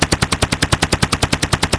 Helicopter Sound
helicopter.mp3